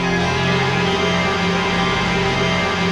ATMOPAD35 -LR.wav